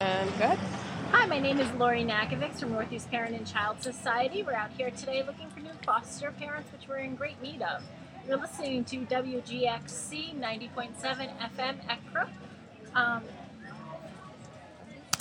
WGXC will broadcast live at the Athens Street Fest...